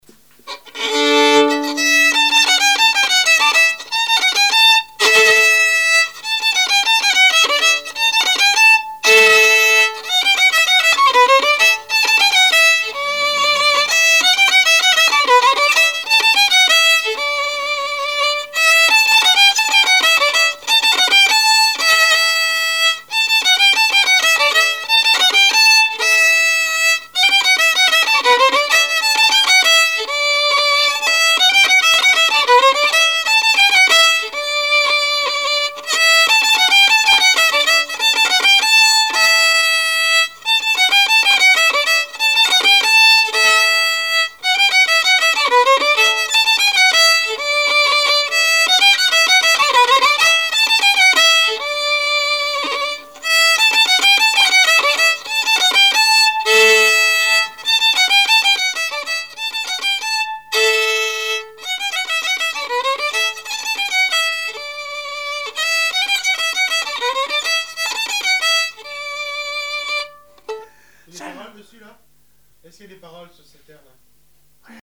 Couplets à danser
branle : courante, maraîchine
Répertoire musical au violon
Pièce musicale inédite